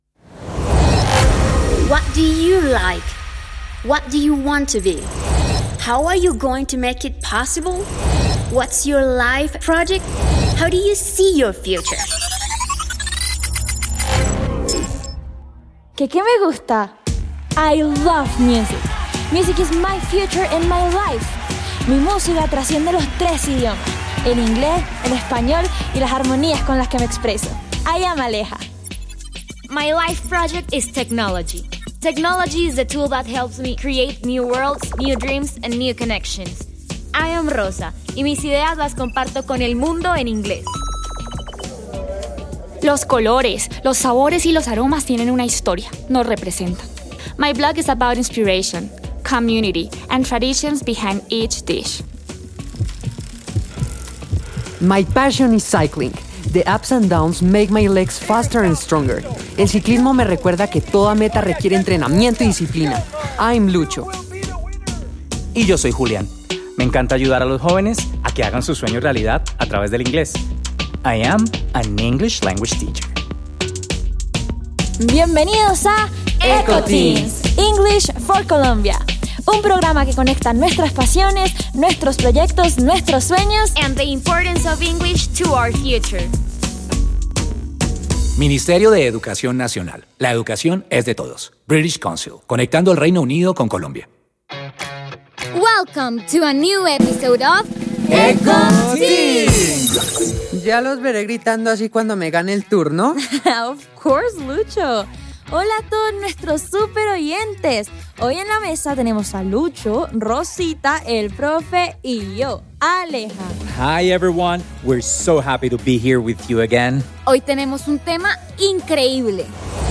Introducción En este episodio se presentan situaciones sobre artesanía y creación manual mediante diálogos en inglés.
Ir a mis descargas Eco Teens Handmade programa radial